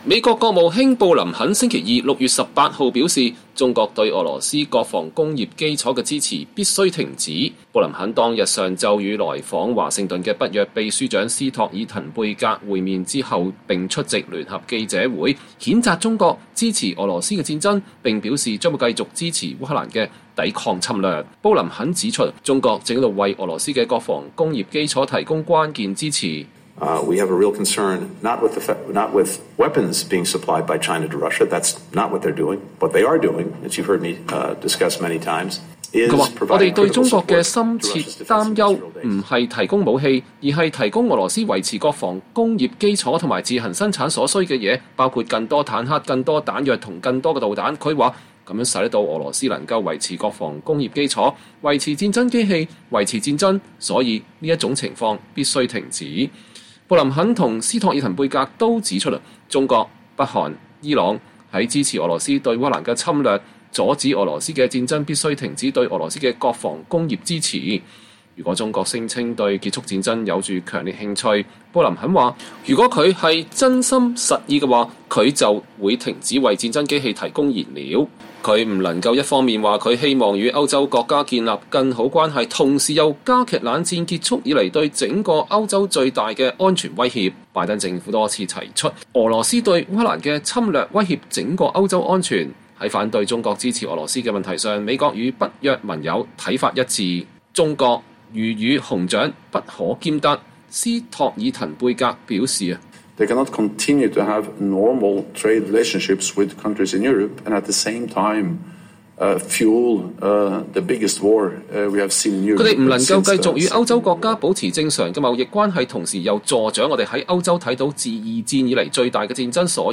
美國國務卿布林肯星期二(6月18日)與來訪華盛頓的北約秘書長斯托爾滕貝格(Jens Stoltenberg)會面並出席聯合記者會。